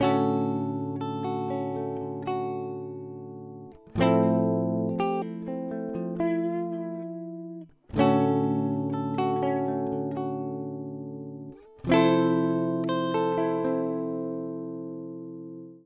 Fender style Amp clean:
DI Signal
fenderduosonicDiSignal.wav